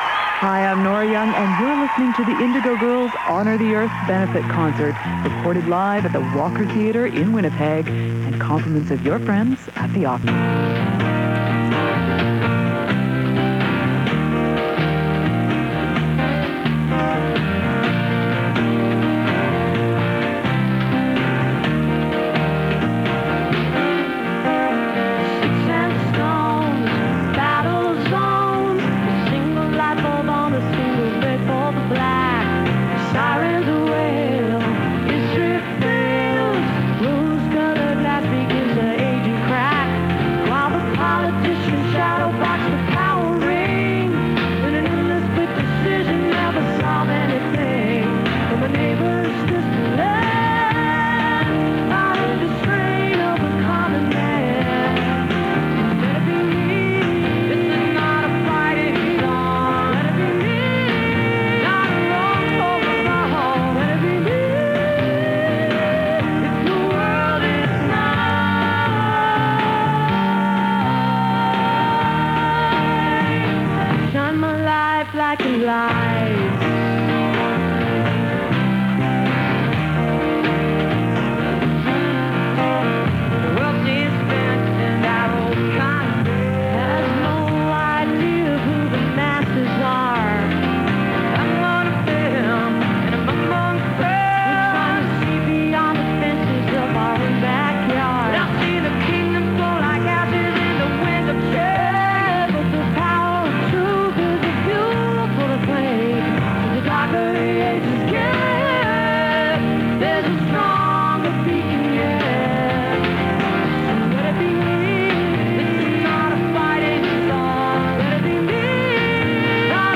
(radio broadcast)